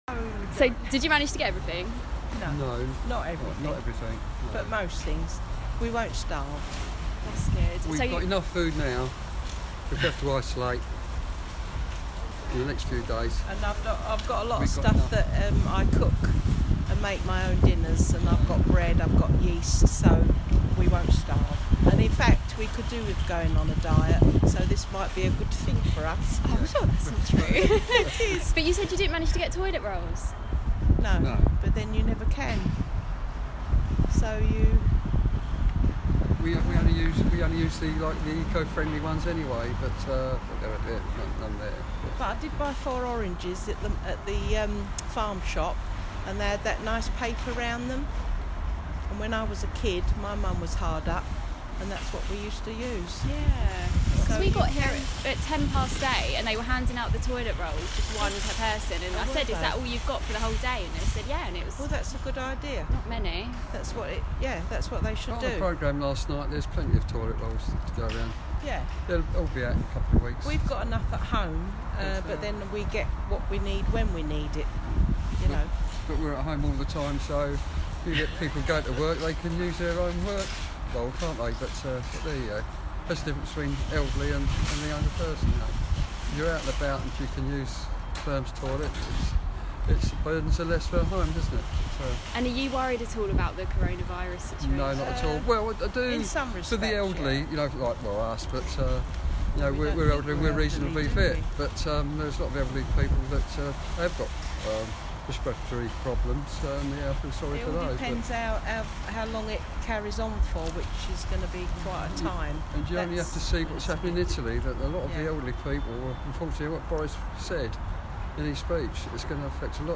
chatting to shoppers at Sainsbury's in Gravesend